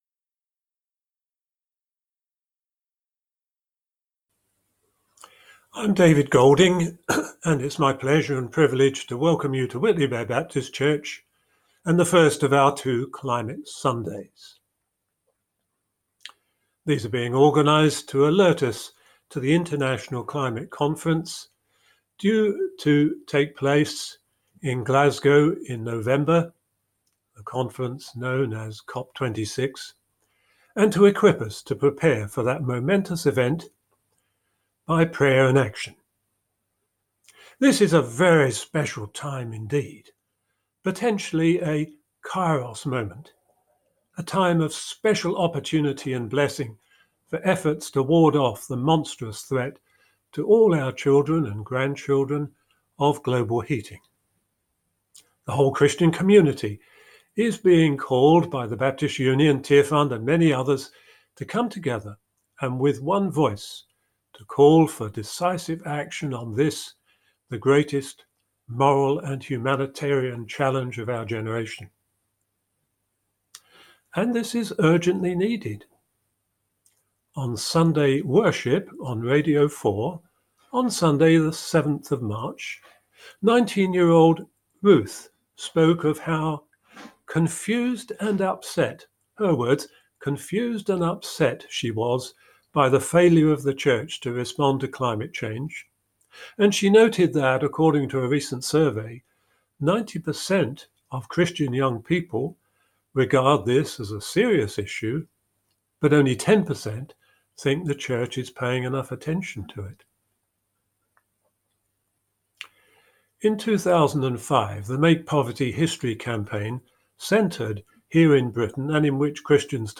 Pre-recorded video and audio.
Morning Service